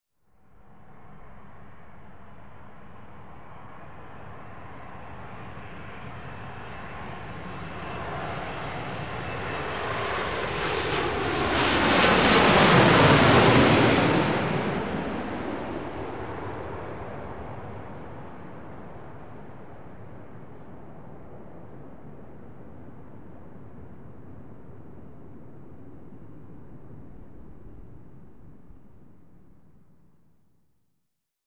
Боинг 787 пролетает над головой